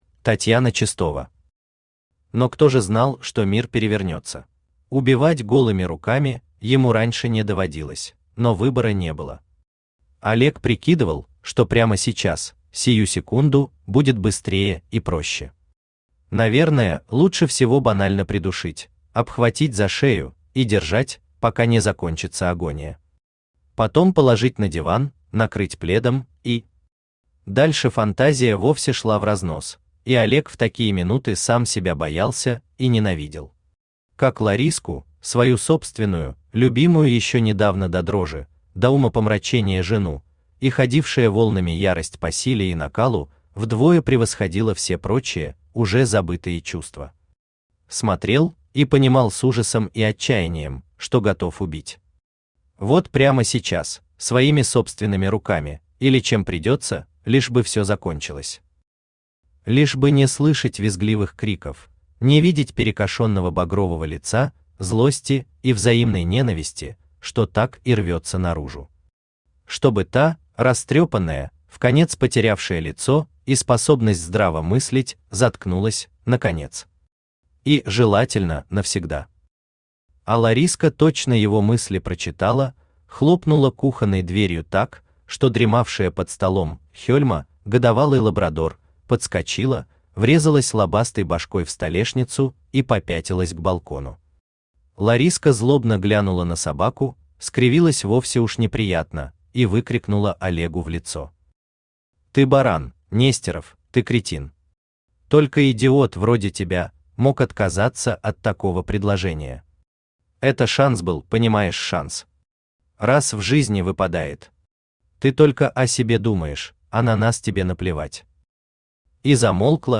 Аудиокнига Но кто же знал, что мир перевернется | Библиотека аудиокниг
Aудиокнига Но кто же знал, что мир перевернется Автор Татьяна Чистова Читает аудиокнигу Авточтец ЛитРес.